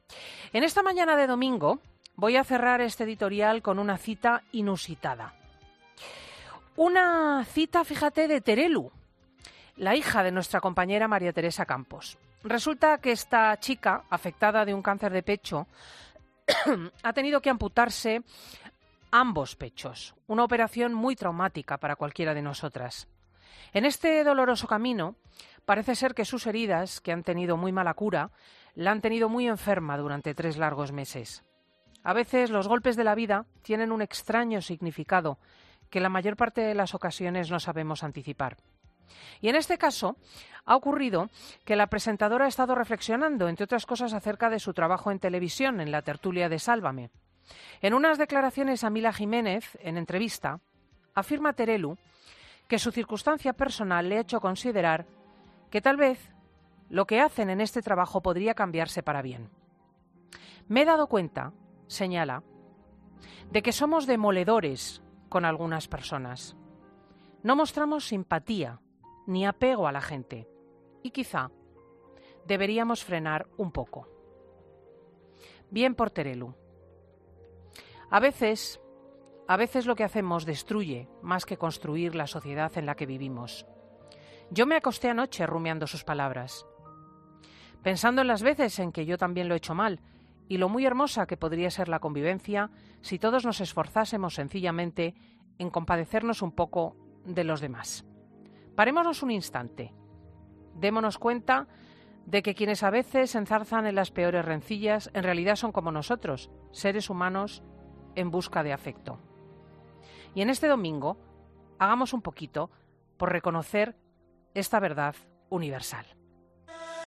"En esta mañana de domingo" dice Cristina "voy a cerrar este editorial con una cita inusitada"